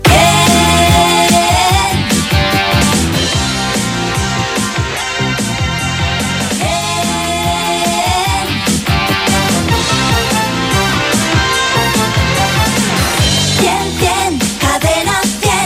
Indicatiu de la cadena.